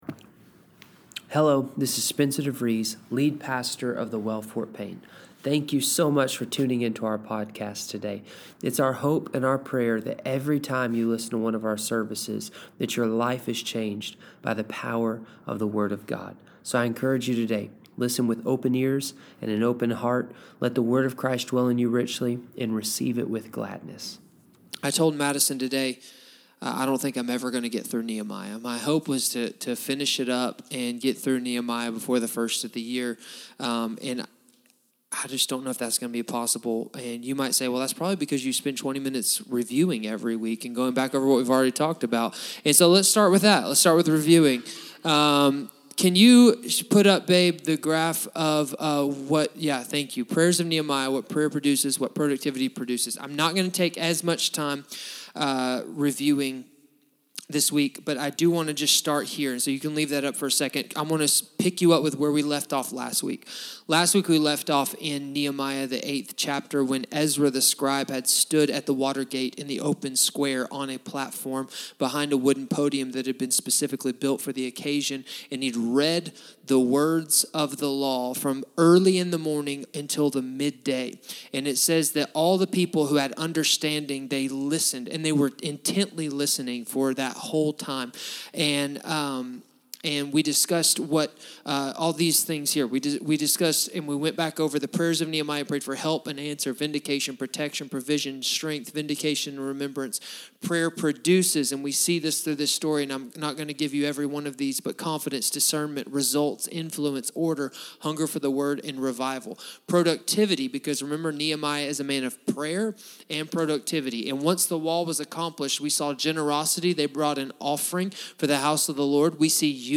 Sermons | The Well Fort Payne